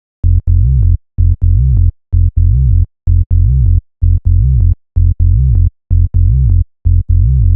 VDE 127BPM Rebound Bass Root F.wav